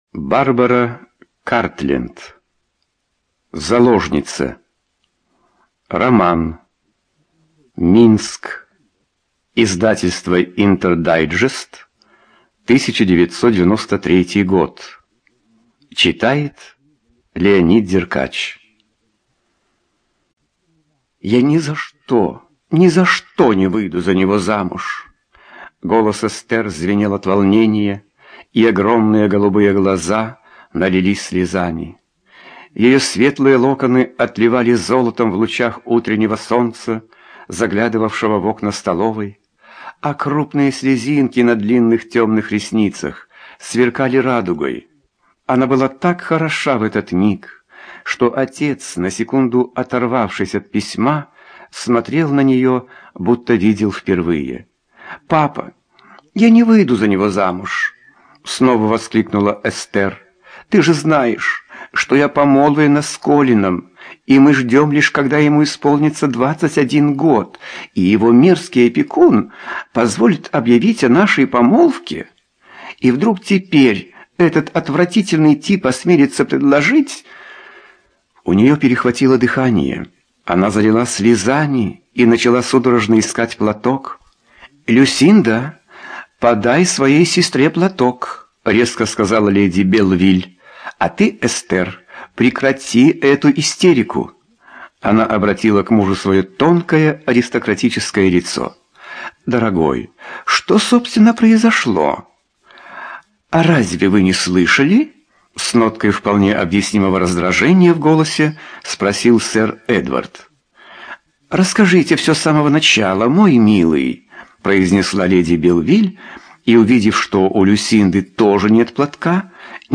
ЖанрЛюбовная проза, Историческая проза
Студия звукозаписизвукотэкс